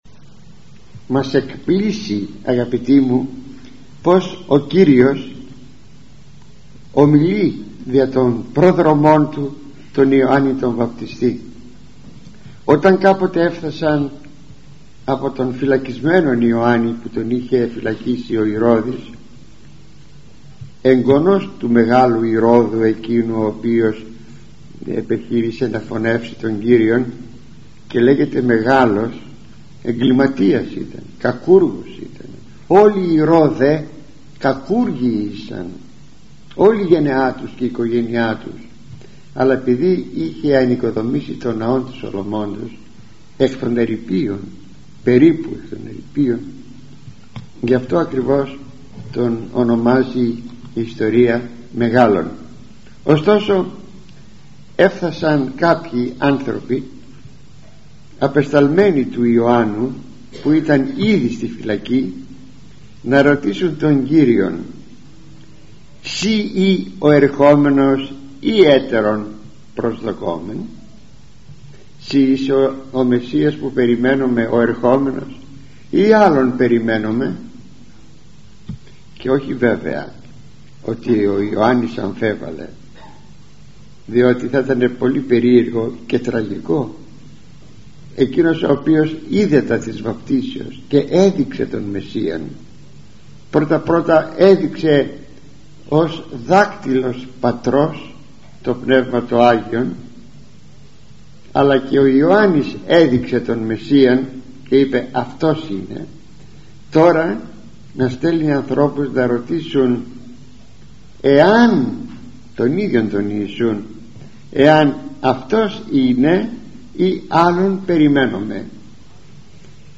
[εκφωνήθηκε στην Ιερά Μονή Κομνηνείου Λαρίσης στις 7-1-2001], (Β΄έκδοσις)